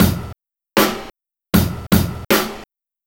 Track 11 - Kick Snare Beat 02.wav